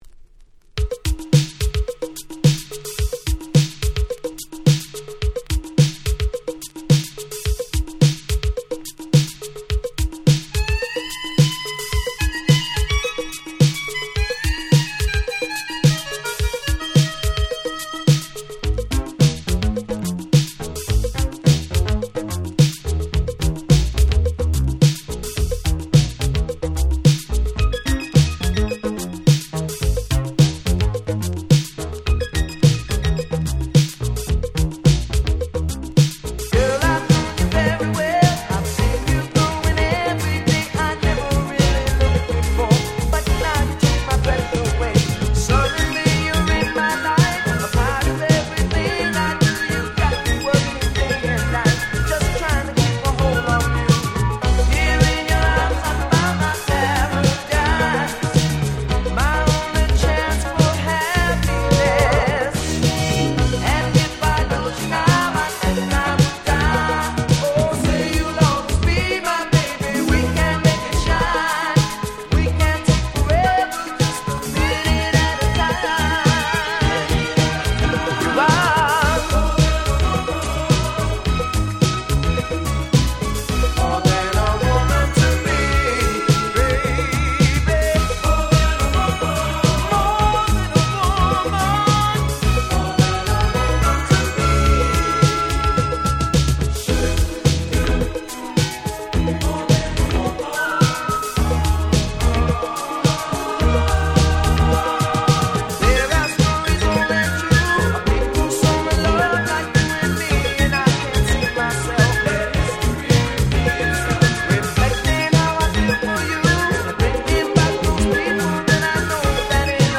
ディスコ Dance Classics